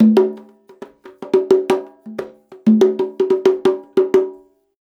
90 CONGA 5.wav